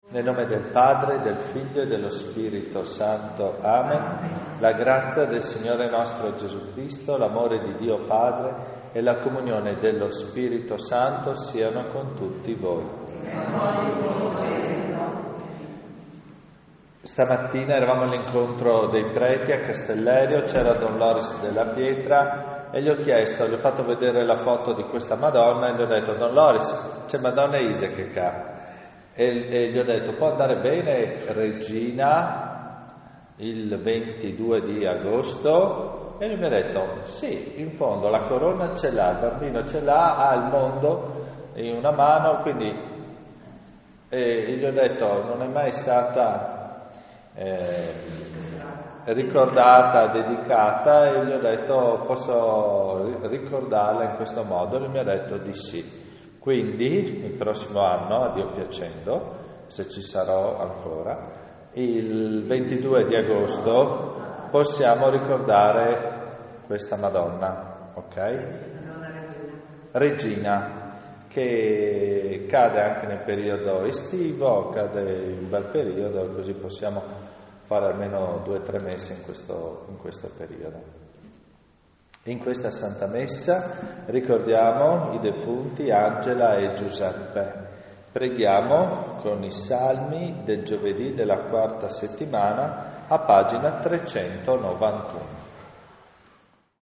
Leproso di Premariacco (UD), 19 Settembre 2024
CANTO E PREGHIERE DI APERTURA